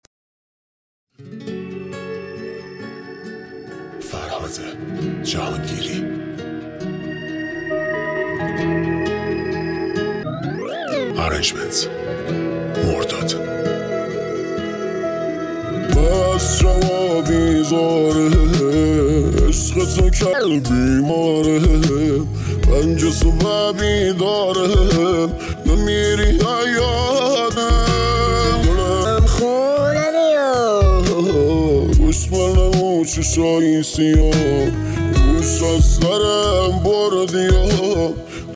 # simple 4 band EQ
Remix